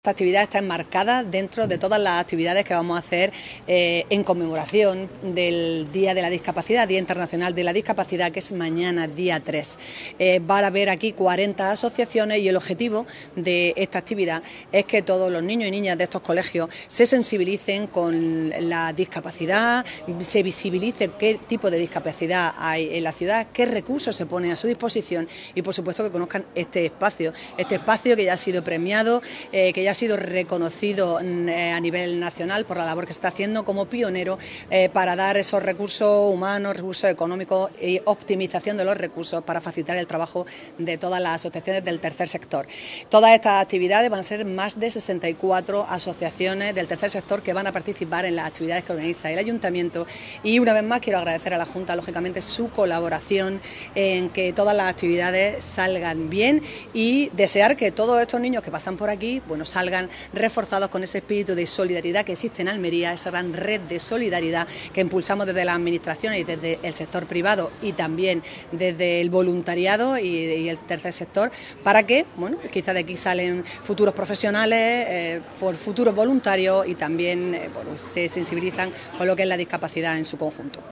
ALCALDESA-PUERTAS-ABIERTAS.mp3